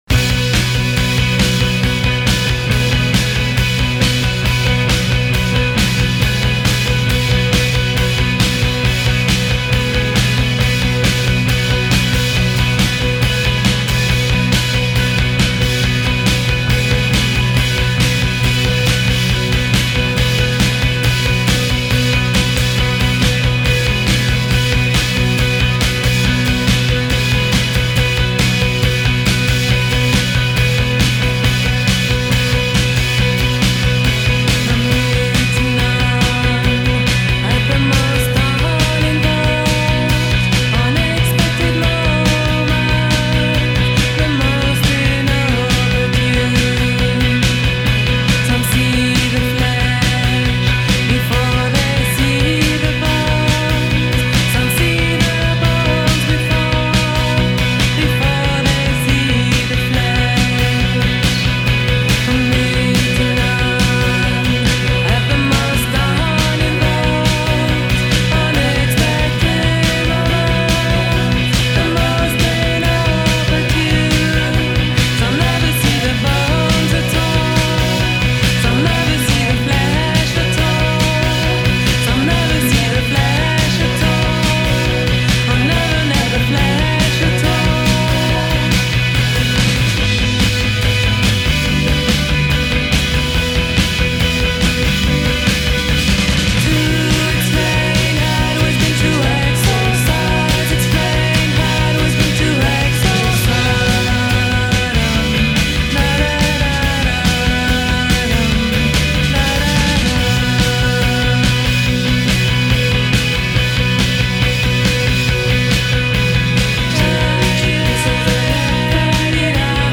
the band established what came to be known as Post-Rock .